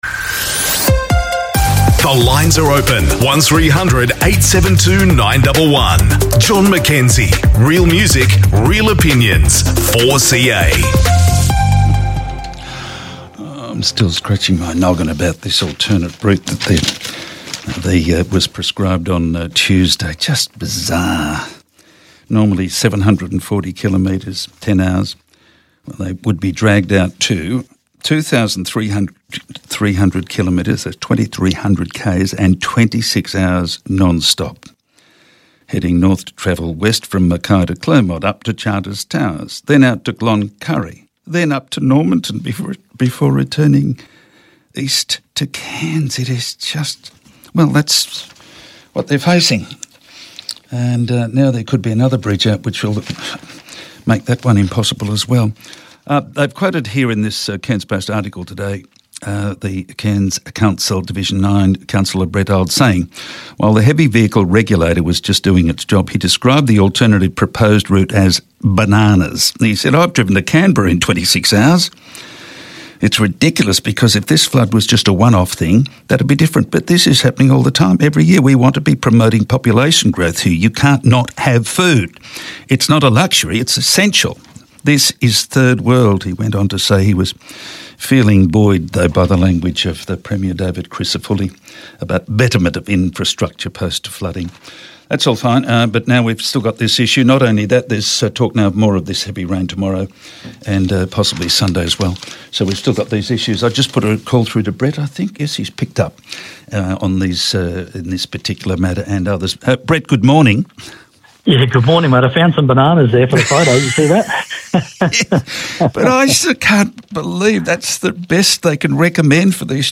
chats with Deputy Mayor Brett Olds, CRC Rep for Division 9